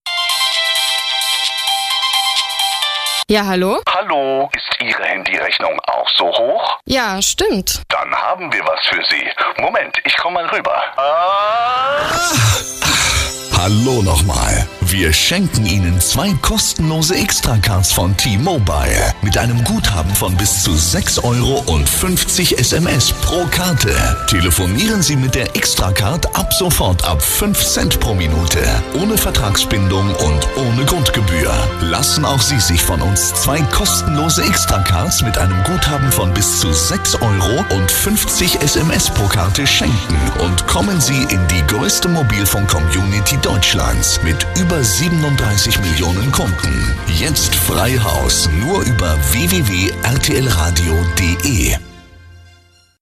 Trailer bei RTL Radio:
xtra-rtl-trailer.mp3